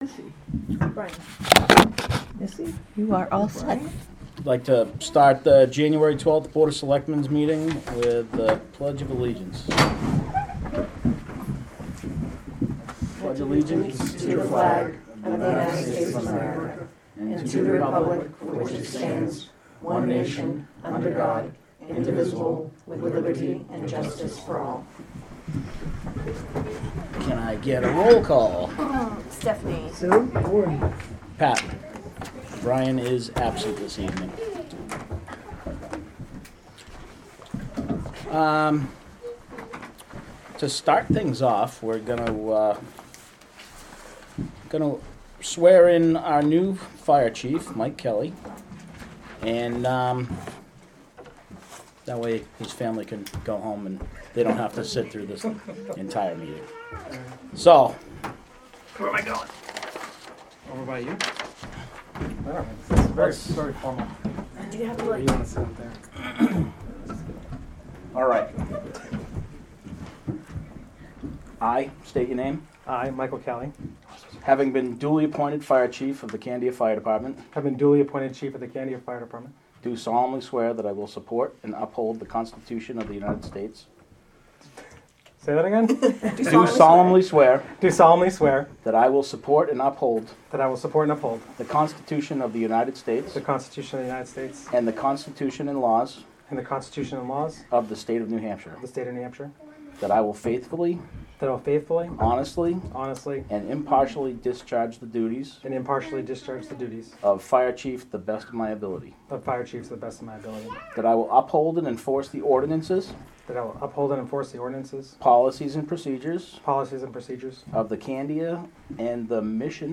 Audio recordings of committee and board meetings.
Board of Selectmen Meeting